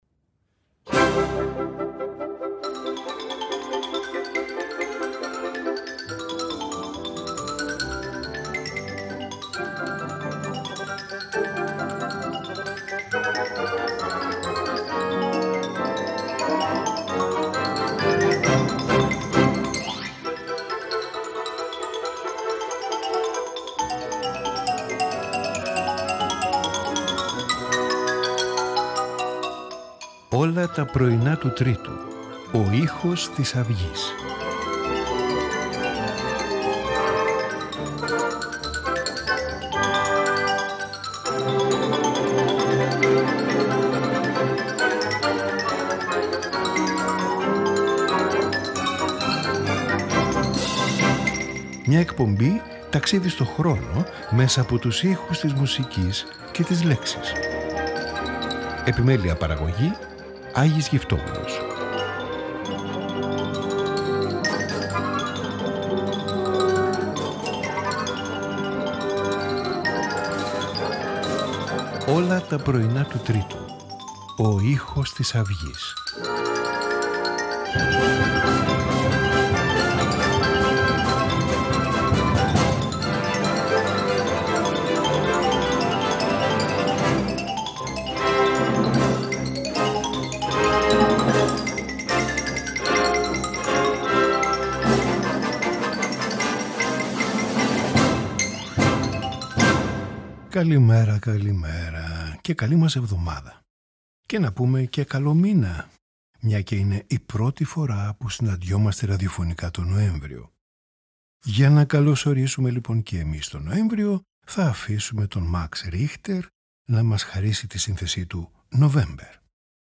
Piano Concerto in D major
String Quartet
Piano Trio in G major
Violin Concerto No.2 in E major